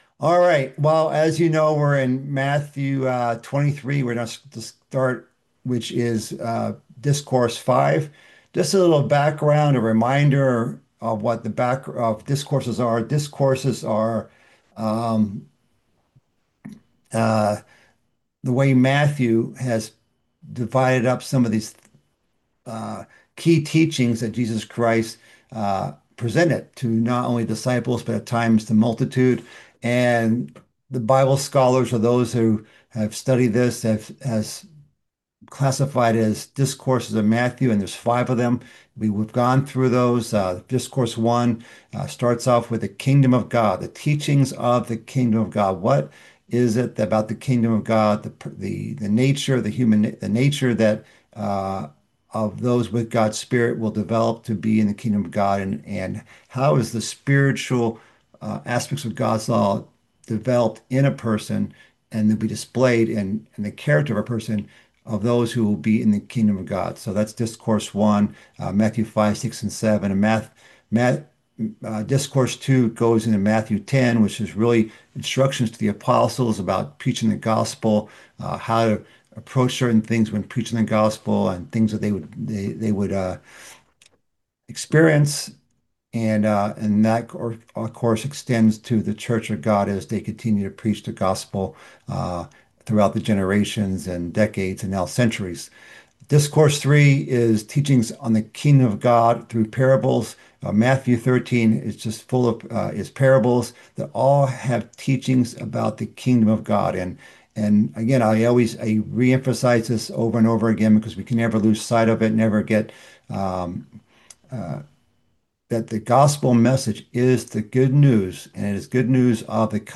This is the first part of a mid-week Bible study series covering Christ's fifth discourse in the book of Matthew. This message delves into the first few verses of Matthew 23, which begins a section about woes to the scribes and Pharisees.